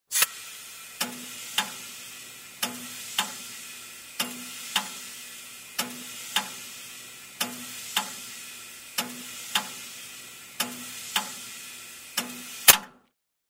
Звук смены колес на летнюю или зимнюю резину в гараже